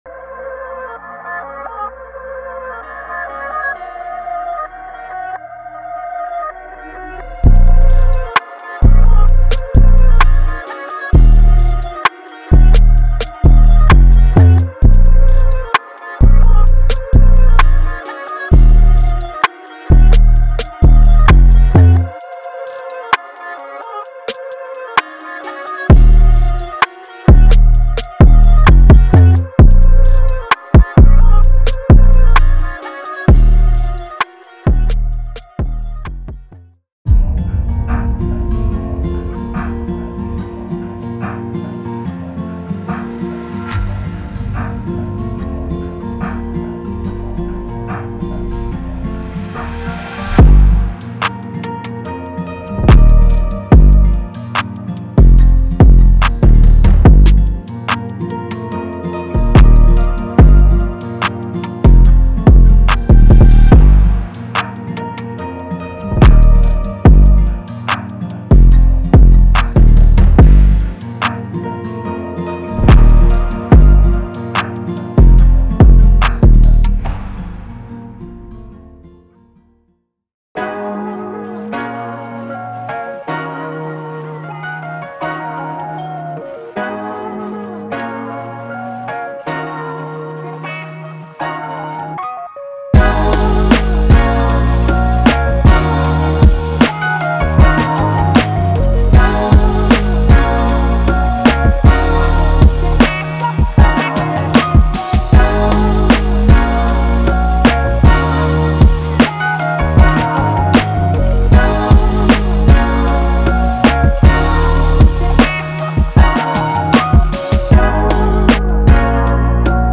Hip HopTrap
从庞大的808年代和脚踏声，到完美的小军鼓和掌声，卡特尔提供了创建危险记录所需的所有鼓声。
内部的旋律设计成与摇摆不定的鼓配对，捕捉了老式嘻哈的经典氛围。
在内部，您会发现大量不同的严重变形的808，它们都经过优化以制作危险轨道。